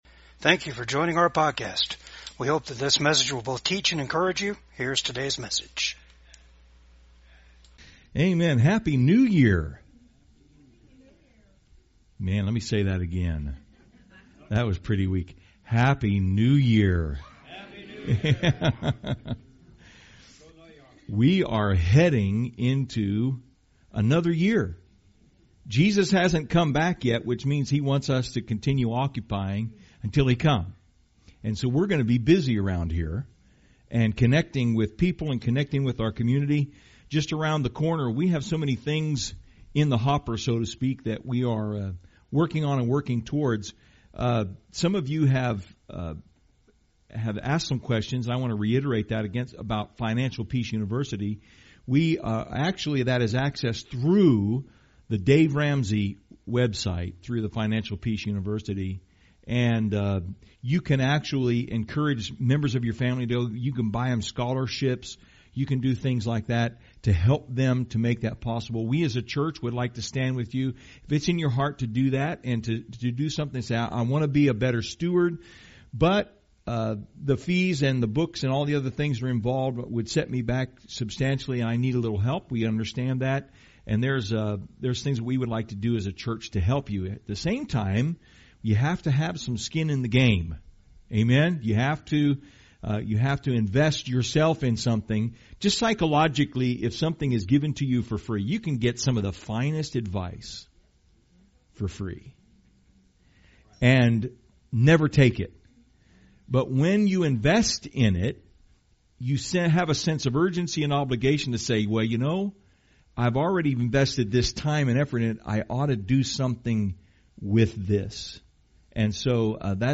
Service Type: VCAG SUNDAY SERVICE